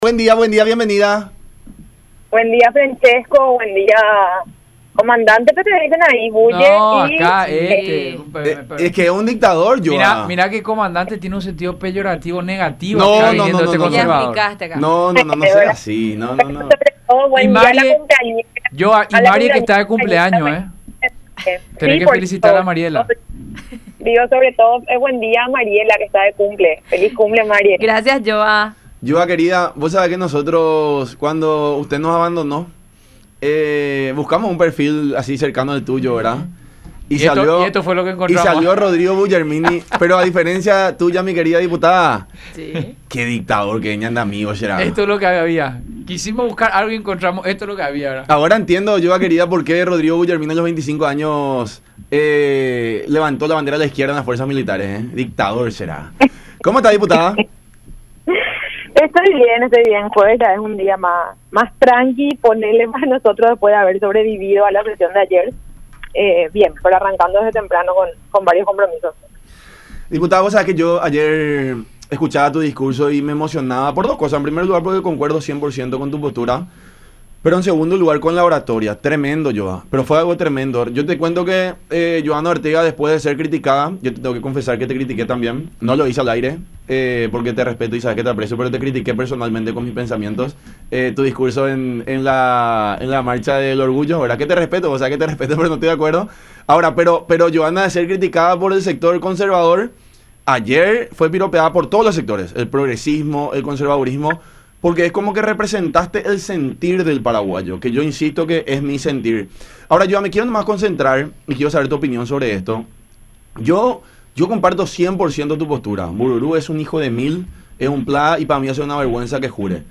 declaró la diputada en charla con “La Unión Hace La Fuerza” por Unión TV y radio La Unión.